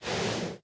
breathe2.ogg